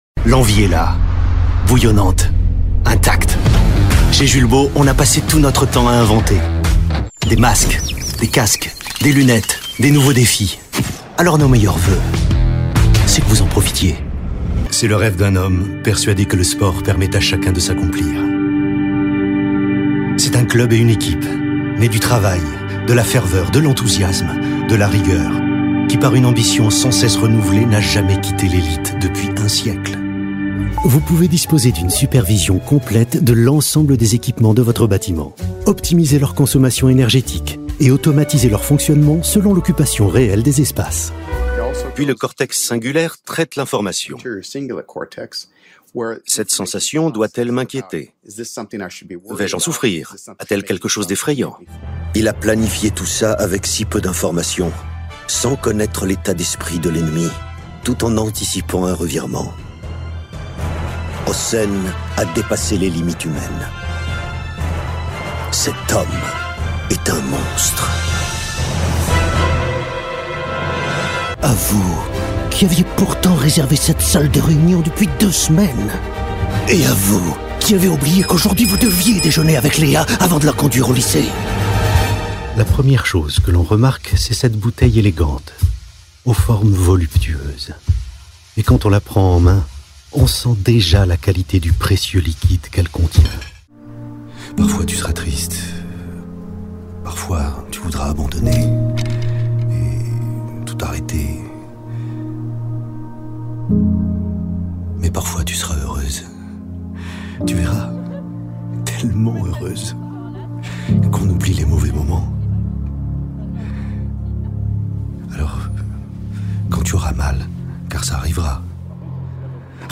Démo voix off
30 - 60 ans - Baryton-basse